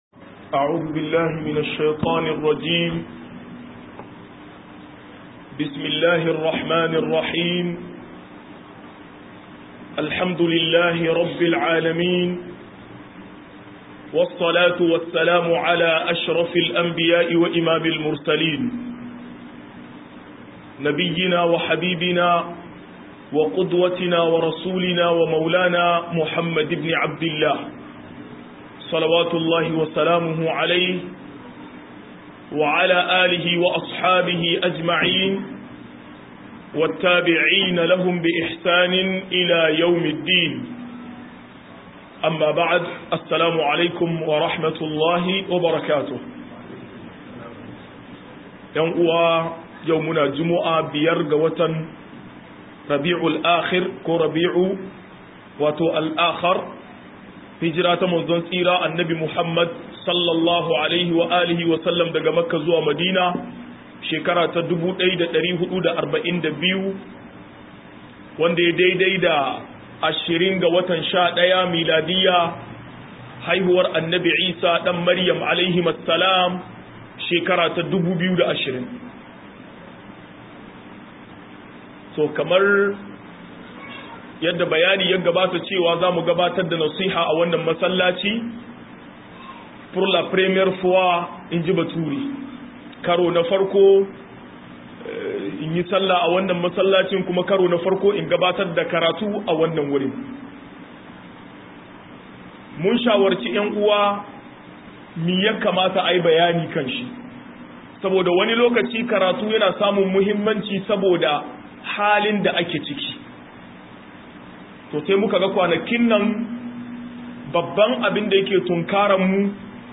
70 - MUHADARA